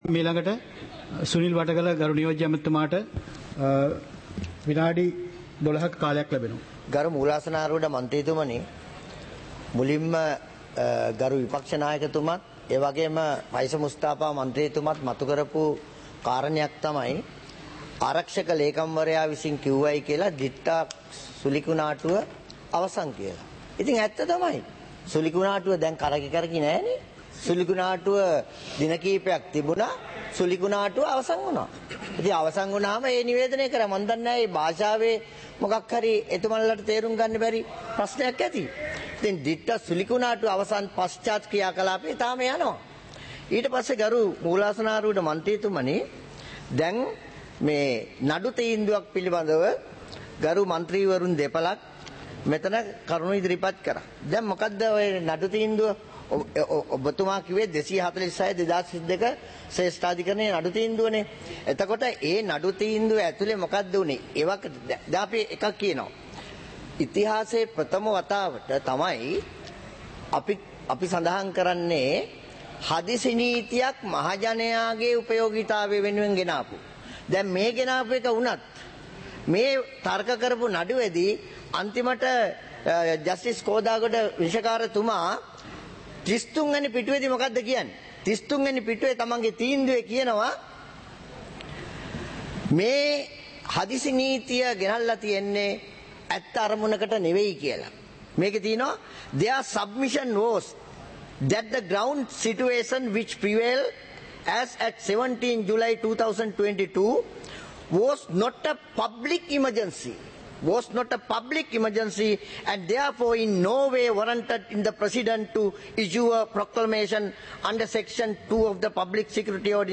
பாராளுமன்ற நடப்பு - பதிவுருத்தப்பட்ட